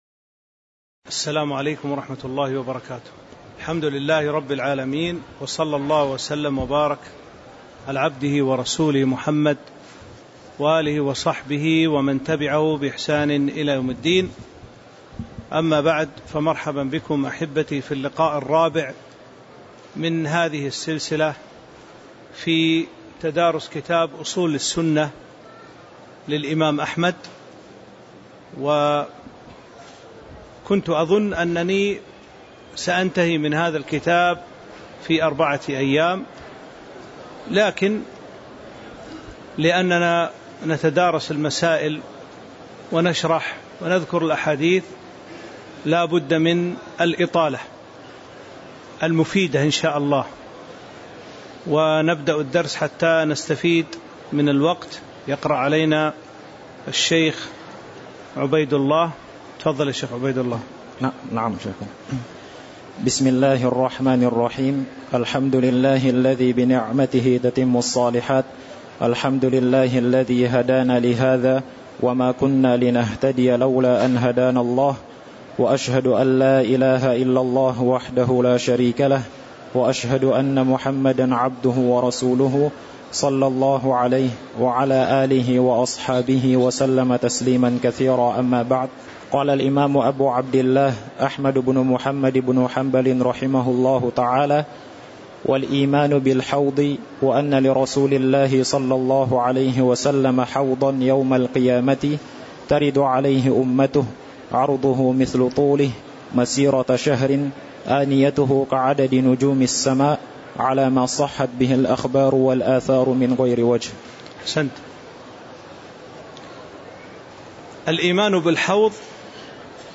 تاريخ النشر ٢٧ صفر ١٤٤٥ هـ المكان: المسجد النبوي الشيخ